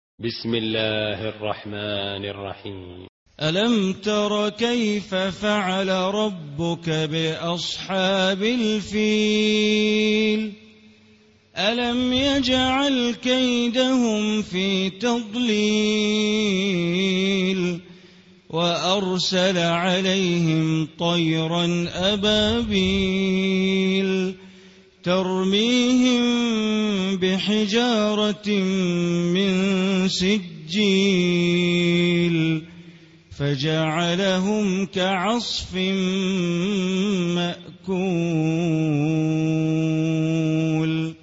Surah Al-Fil Recitation by Sheikh Bandar Baleela
Surah Al-Fil, listen online mp3 tilawat / recitation in Arabic in the beautiful voice of Imam e Kaaba Sheikh Bandar Baleela.